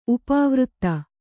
♪ upāvřtta